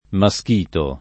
Maschito [ ma S k & to ]